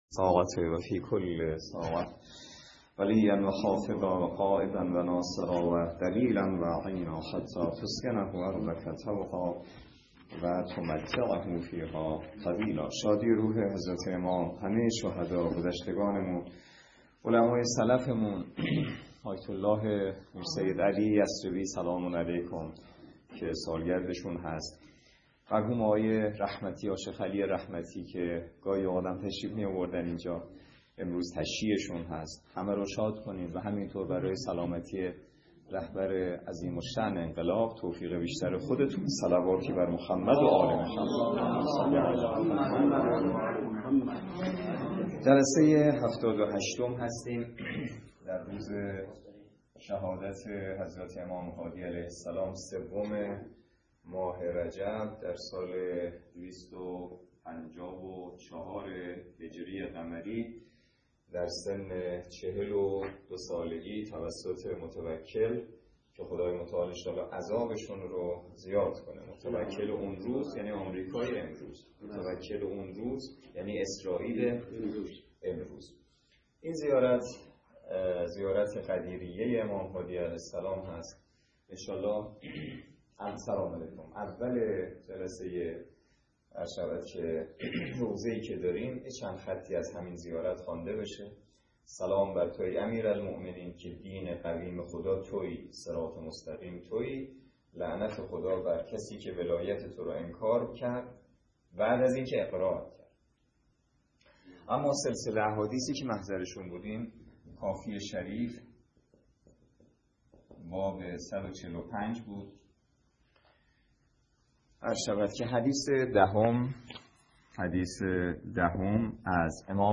درس فقه الاجاره نماینده مقام معظم رهبری در منطقه و امام جمعه کاشان - سال سوم جلسه هفتاد و هشت